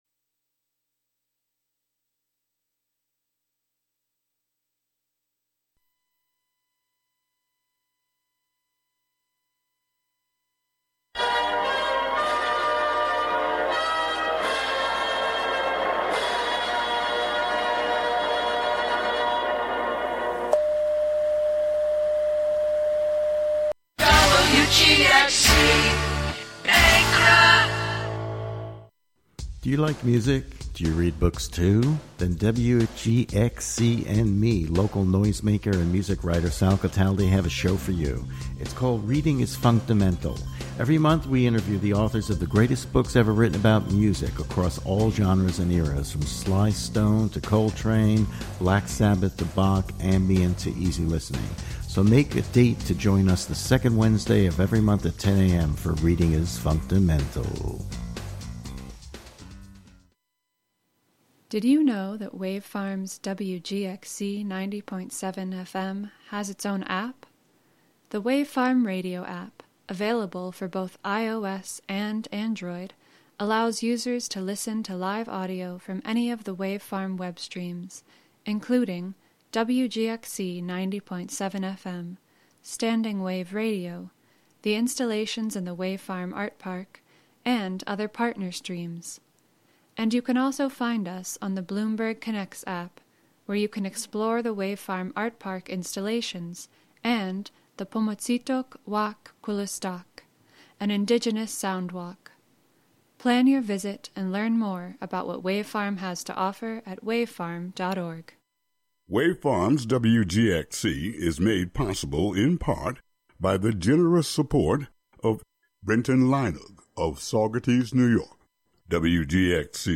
7pm "Foraging Ahead" features music and interviews fro...
broadcast live from WGXC's Catskill studio.